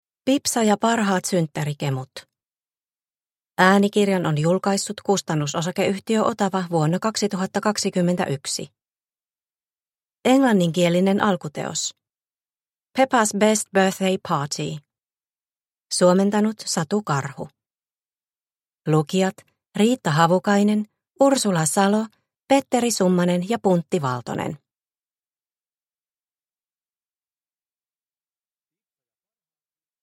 Pipsa ja parhaat synttärikemut – Ljudbok – Laddas ner